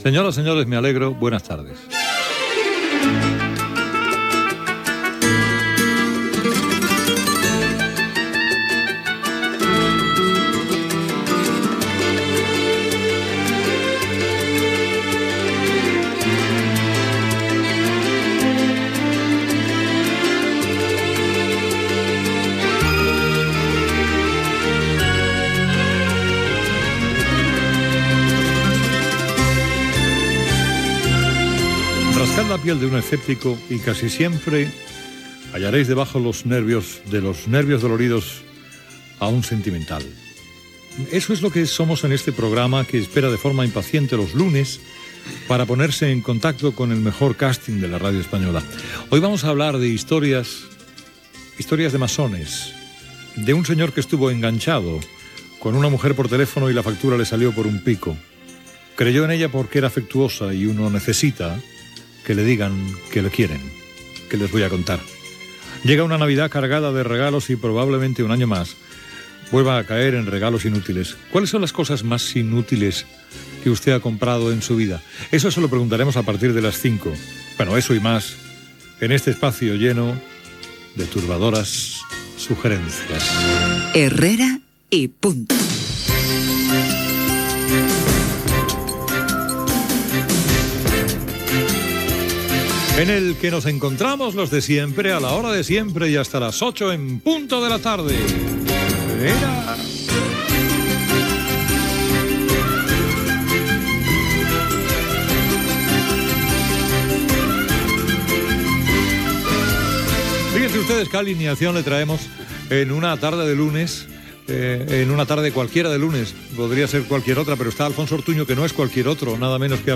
Inici del programa d'un dilluns de desembre, sumari i presentació dels tertulians